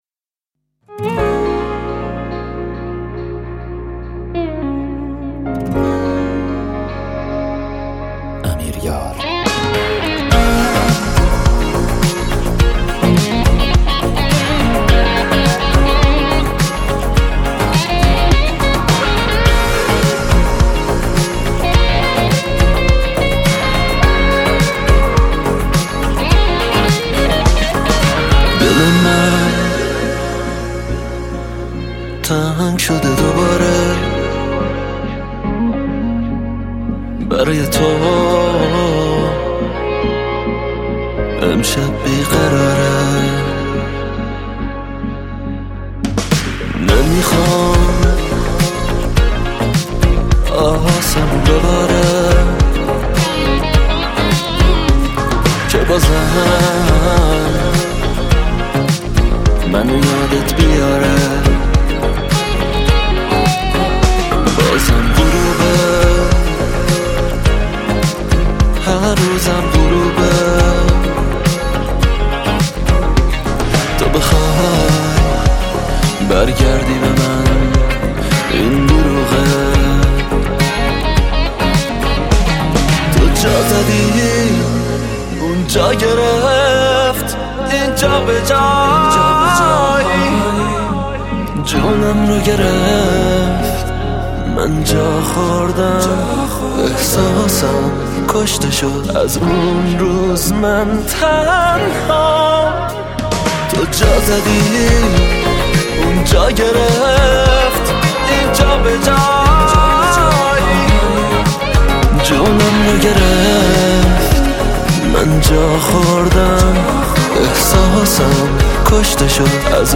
پاپ
آهنگ غمگین آهنگ محلی